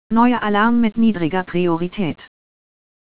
AlarmMinor.wav